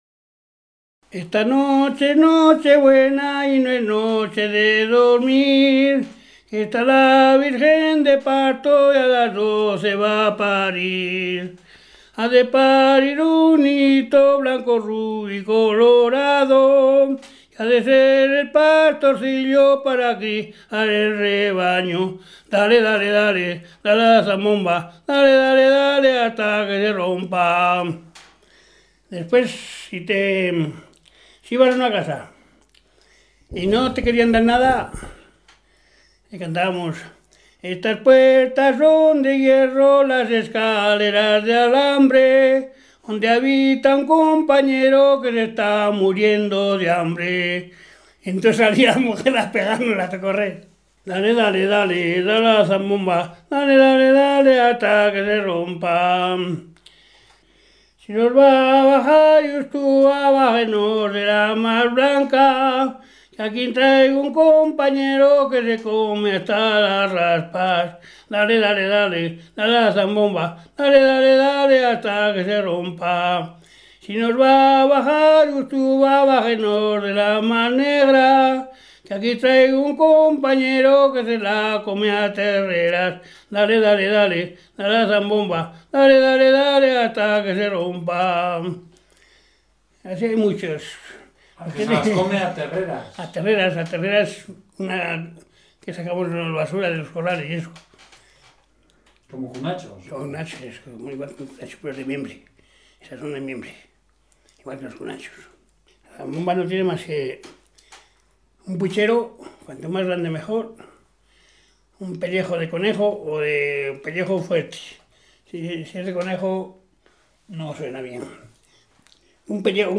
Clasificación: Cancionero
Lugar y fecha de recogida: Grávalos, 9 de agosto de 2001